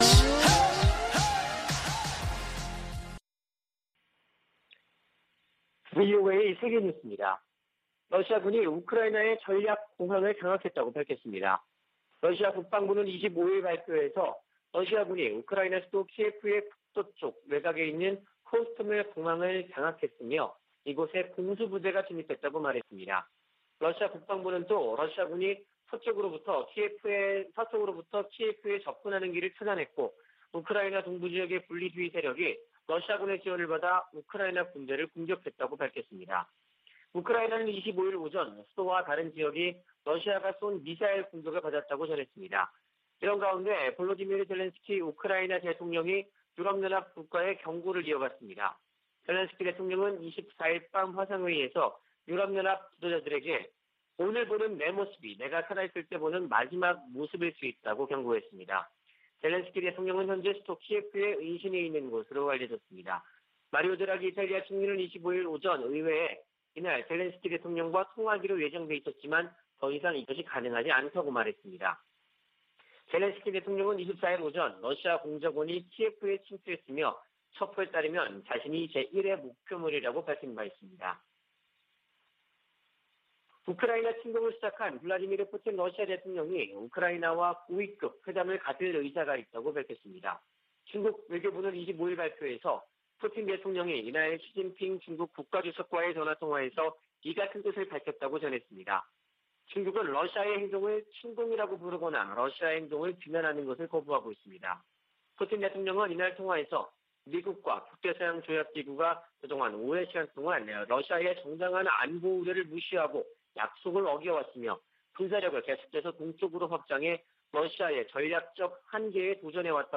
VOA 한국어 아침 뉴스 프로그램 '워싱턴 뉴스 광장' 2021년 2월 26일 방송입니다. 미 공화당 의원들은 러시아의 우크라이나 무력 침공에 대해 북한 등에 미칠 악영향을 우려하며 강력한 대응을 촉구했습니다. 우크라이나 침공으로 조 바이든 행정부에서 북한 문제가 뒤로 더 밀리게 됐다고 미국 전문가들은 진단했습니다. 유엔은 인도주의 기구들의 대북 송금이 막힌 문제를 해결하기 위해 특별 해법을 논의하고 있다고 밝혔습니다.